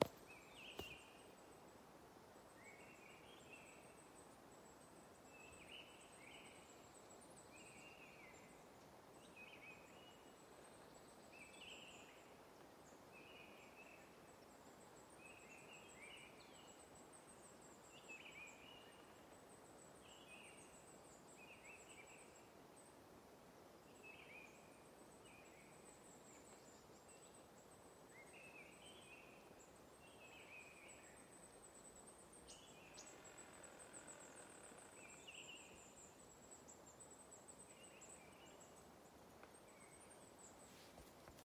Sila strazds, Turdus viscivorus
Administratīvā teritorijaValkas novads
StatussDzied ligzdošanai piemērotā biotopā (D)